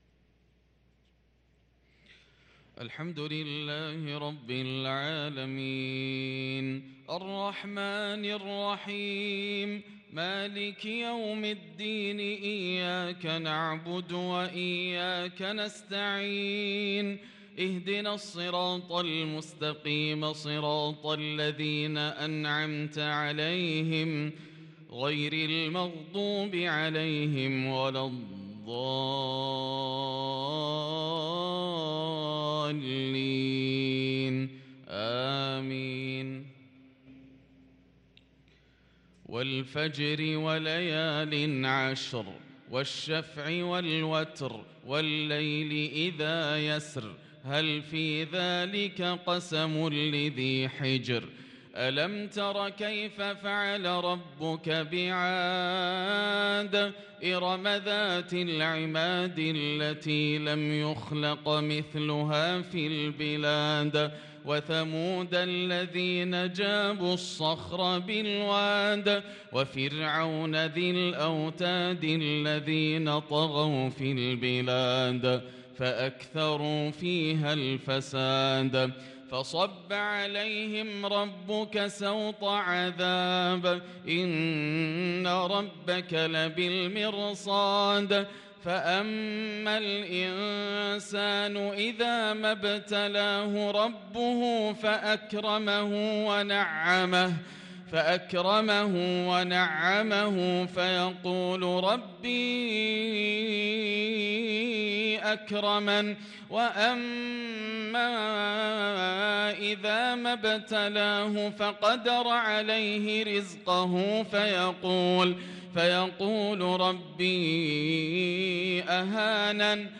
صلاة العشاء للقارئ ياسر الدوسري 20 شوال 1443 هـ
تِلَاوَات الْحَرَمَيْن .